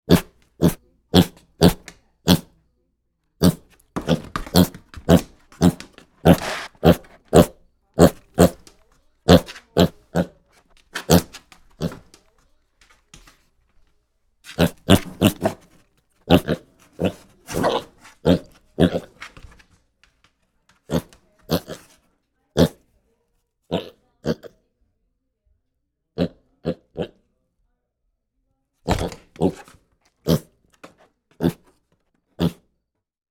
Звуки поросенка
Звук дыхания и фырканья маленького поросенка